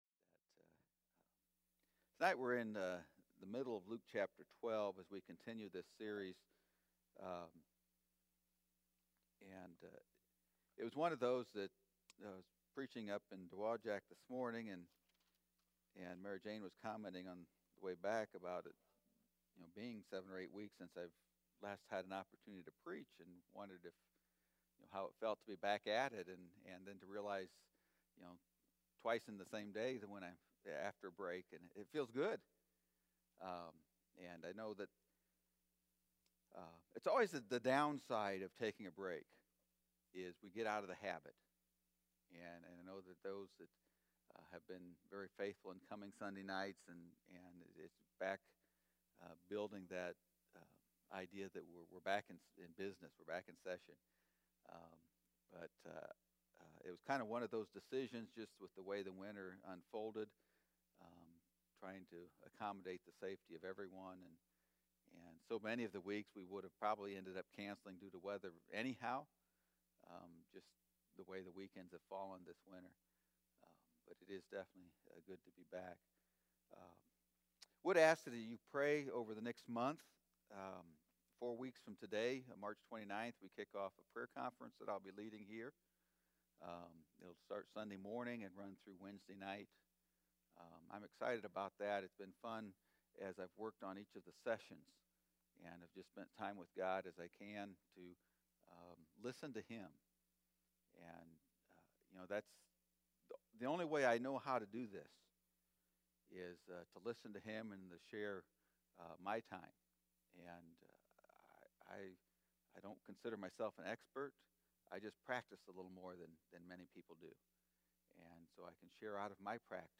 Sermon from the "Living Like Christ" series.